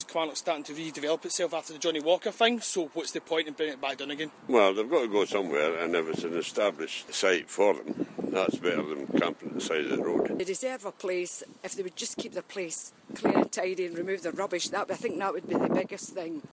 Kilmarnock locals give us their views on the site.